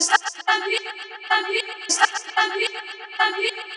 • chopped vocals ping pong house delayed (10).wav
chopped_vocals_ping_pong_house_delayed_(10)_Ec8.wav